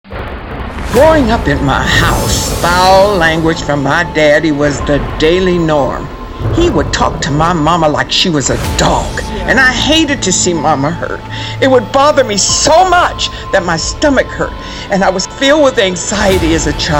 Post-Mastering Sample with Sound Effects